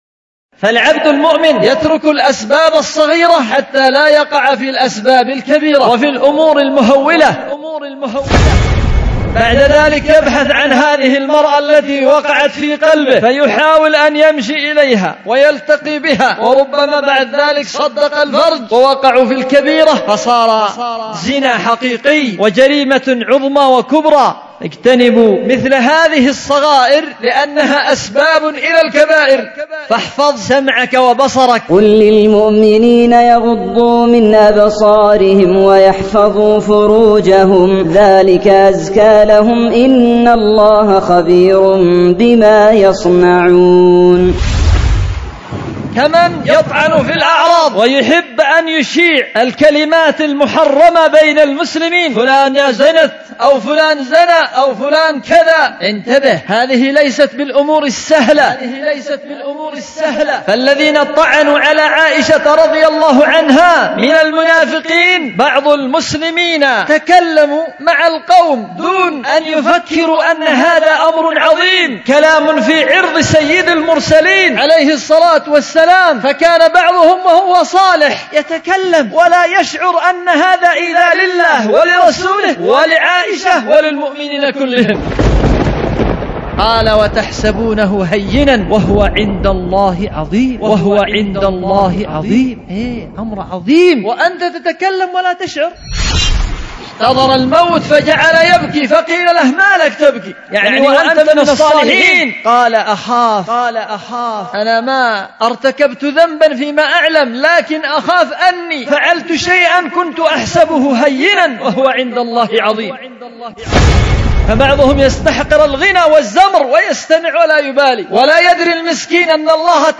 خطبة
ألقيت في دار الحديث بمسجد السلف الصالح بذمار